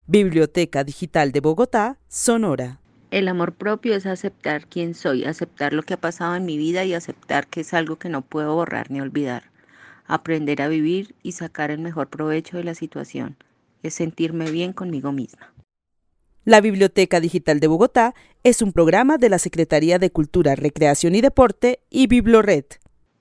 Narración oral de una mujer que vive en la ciudad de Bogotá y que describe el amor propio desde la aceptación propia y todas las situaciones que le han pasado para sacarles provecho. El testimonio fue recolectado en el marco del laboratorio de co-creación "Postales sonoras: mujeres escuchando mujeres" de la línea Cultura Digital e Innovación de la Red Distrital de Bibliotecas Públicas de Bogotá - BibloRed.